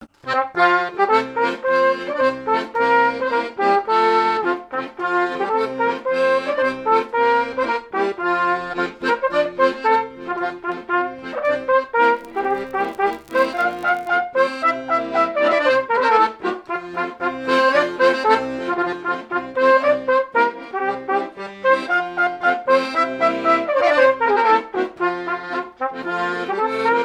danse : polka des bébés ou badoise
airs de danses issus de groupes folkloriques locaux
Pièce musicale inédite